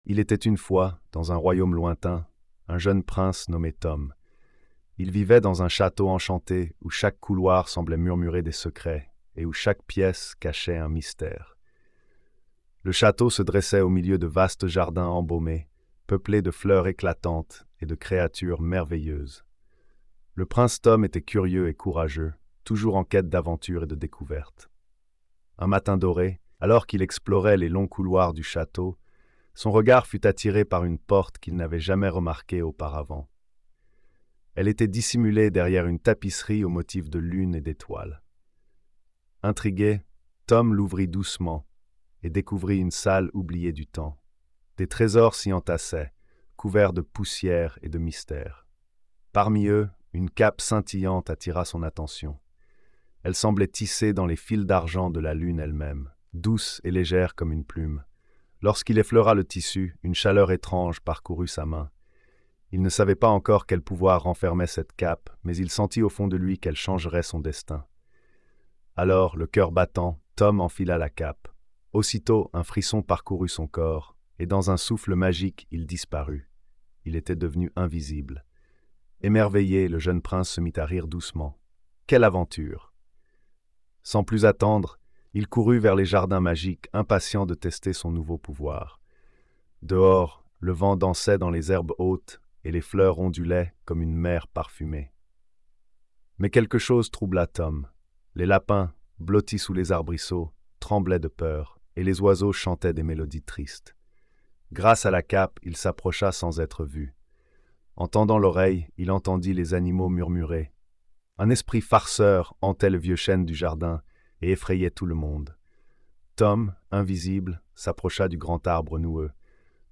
Le Prince Tom et le Château - Conte de fée
🎧 Lecture audio générée par IA